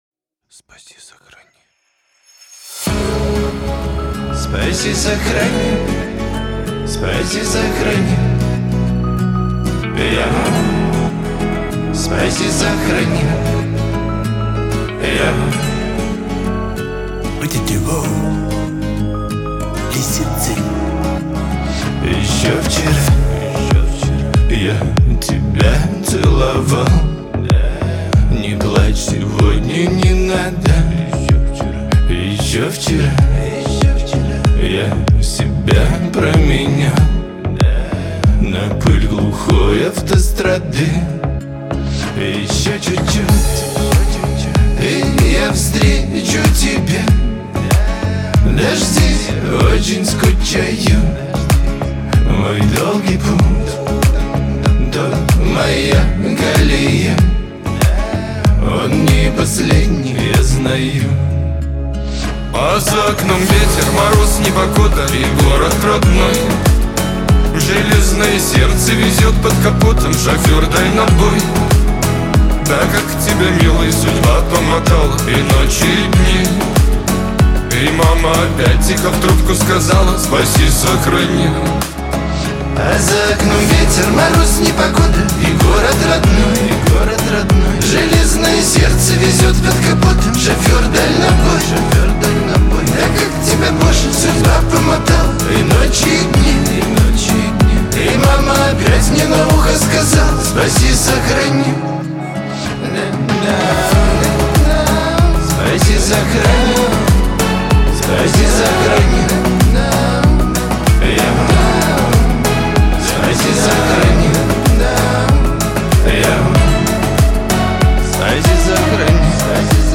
Шансон
Лирика